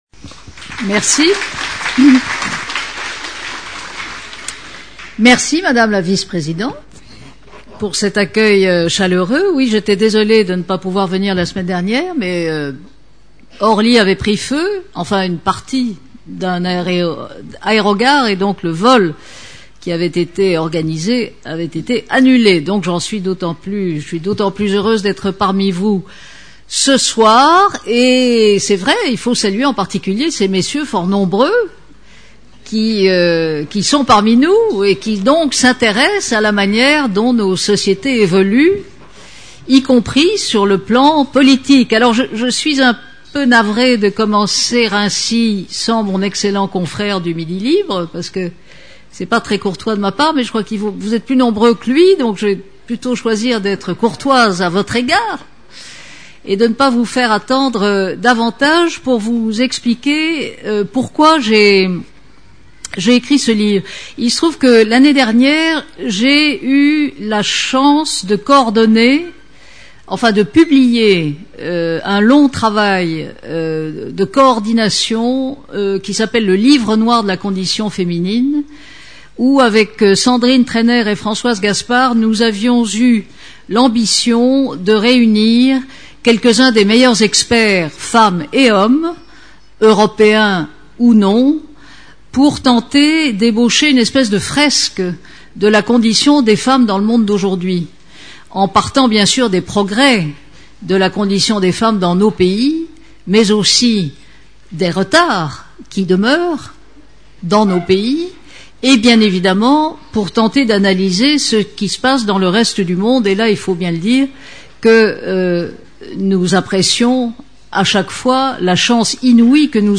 Rencontre avec Christine Ockrent
Ockrent, Christine. Personne interviewée